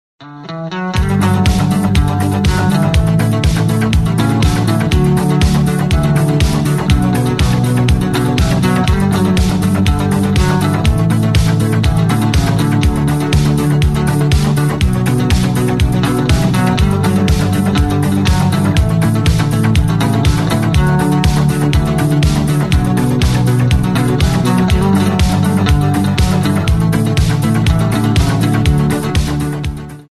Главная » Файлы » Рок